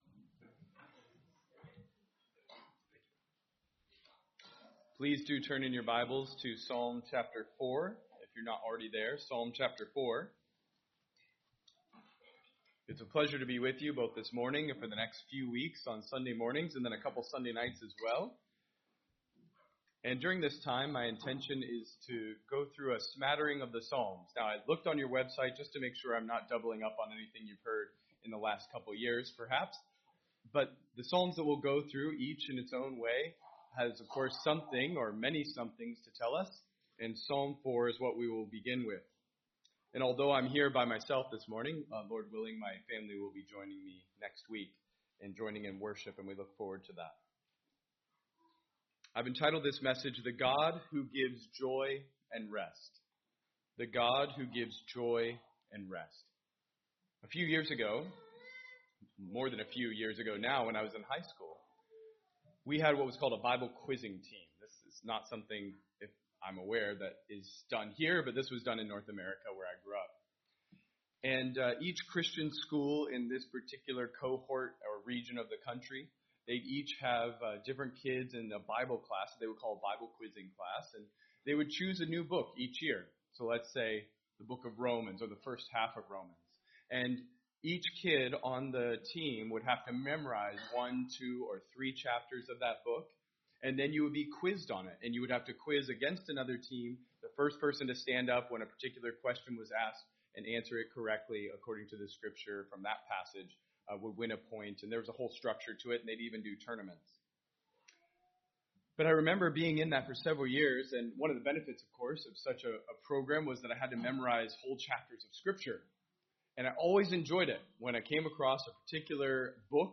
Sermons , Visiting Speakers